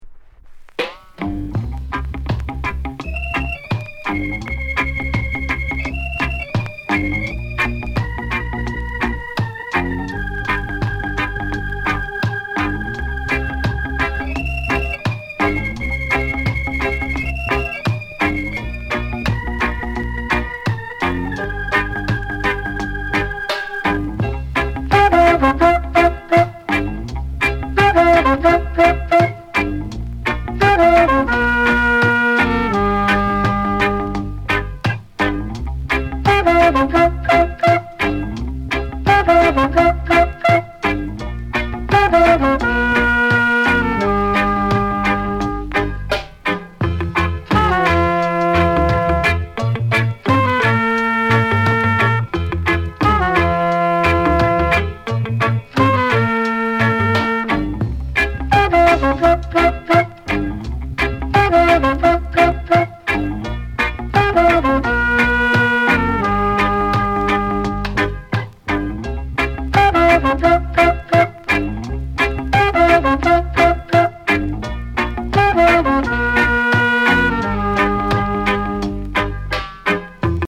RARE instrumental version